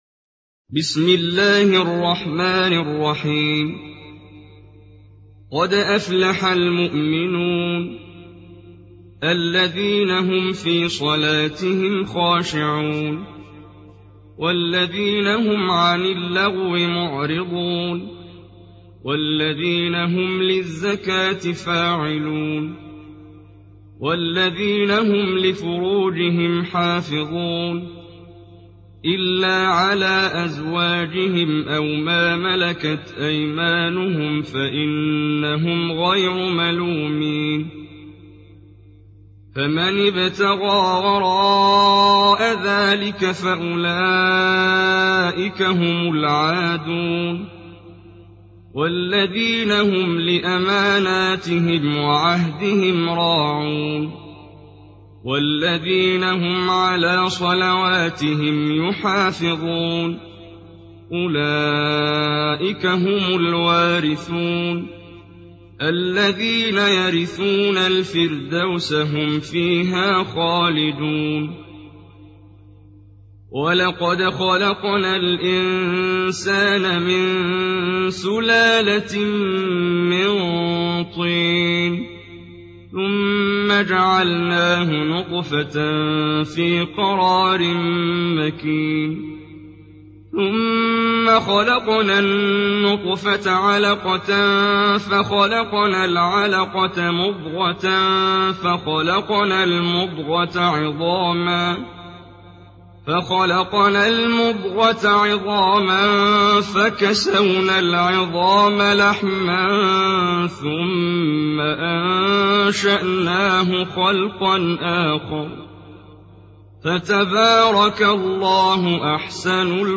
تحميل سورة المؤمنون mp3 بصوت محمد جبريل برواية حفص عن عاصم, تحميل استماع القرآن الكريم على الجوال mp3 كاملا بروابط مباشرة وسريعة